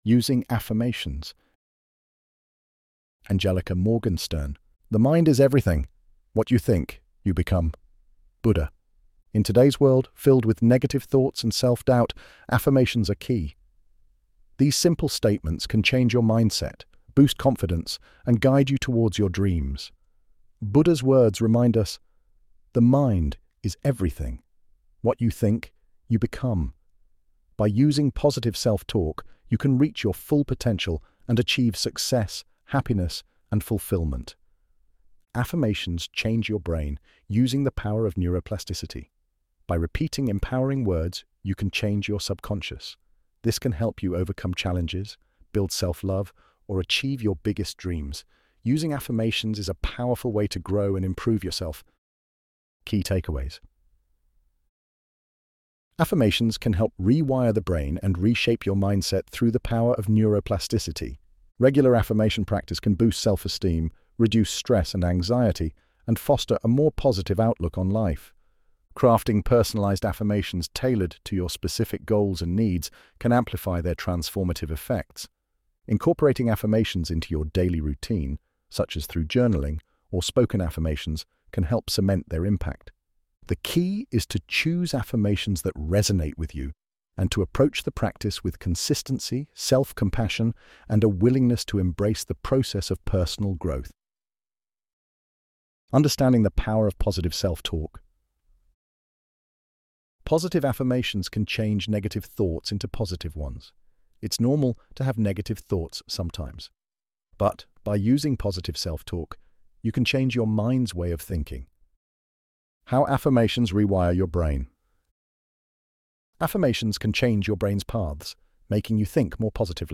ElevenLabs_Using_Affirmations.mp3